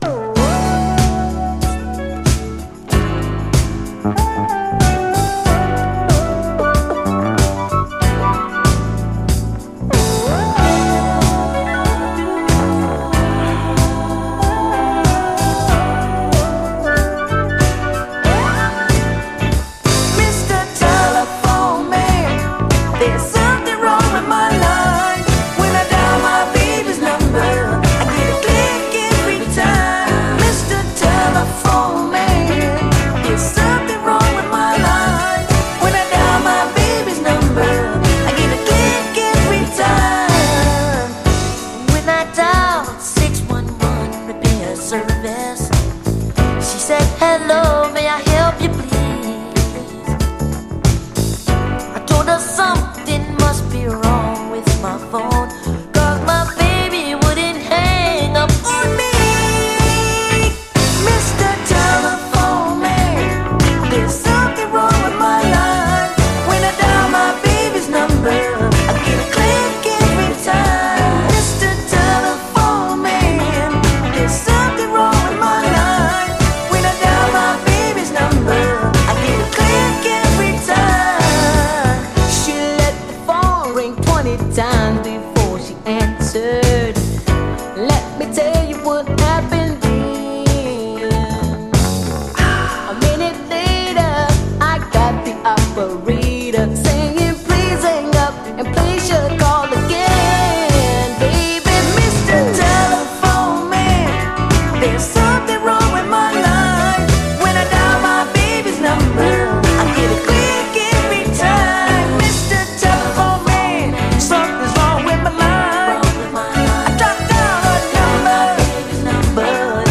SOUL, 70's～ SOUL, 7INCH
愛され続ける80’S胸キュン・キッズ・ソウル・クラシック！